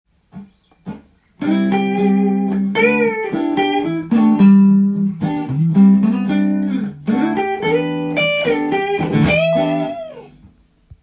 Emペンタトニックスケールを使い感覚で弾いていきます。
ペンタトニックスケールを使うとロックやブルースのような雰囲気になるので